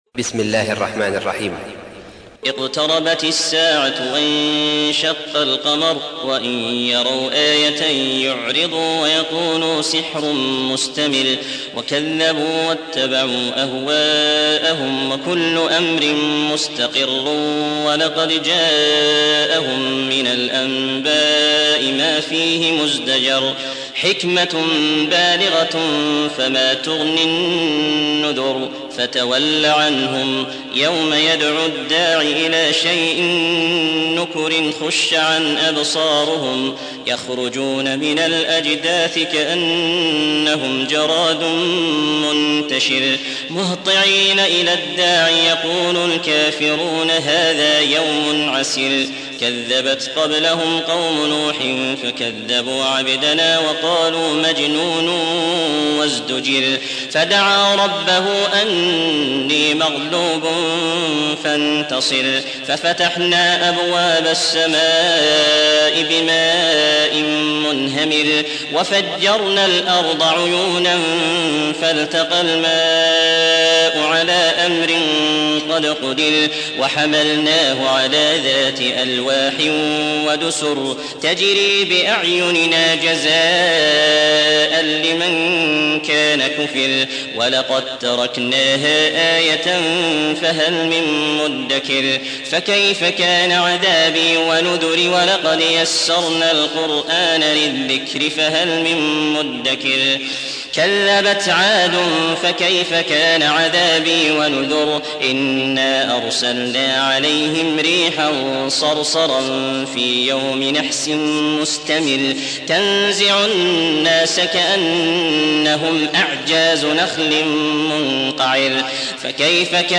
54. سورة القمر / القارئ